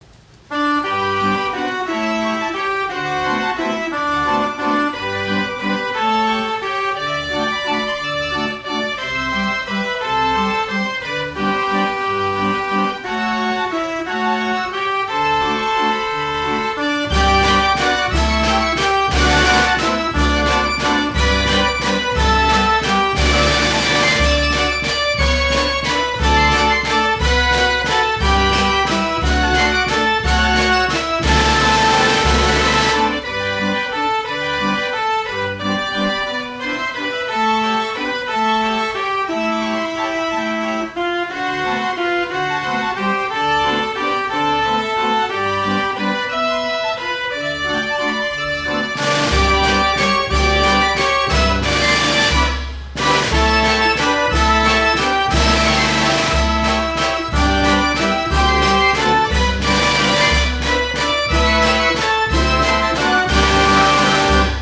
It has 54 keys and plays from paper rolls of music.